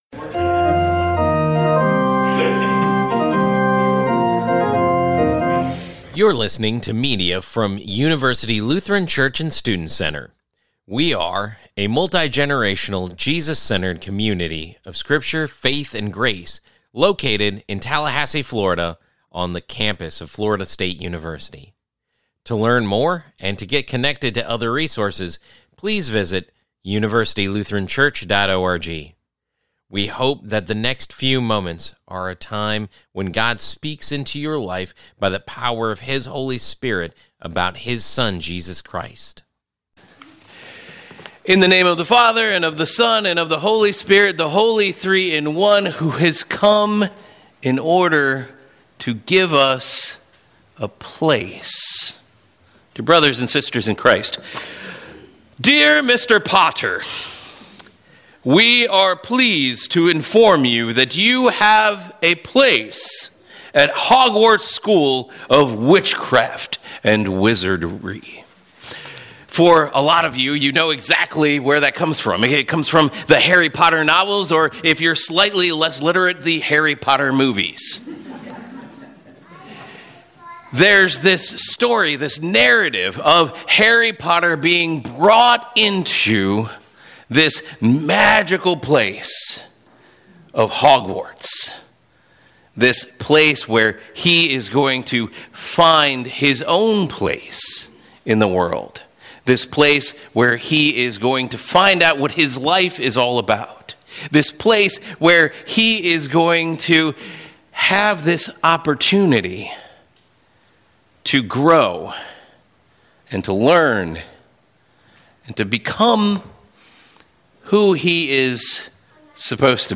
We start off a new sermon series called “RSVP” in which we consider the Epiphany reality that Jesus has come to prepare a place for us in His Kingdom through our baptisms.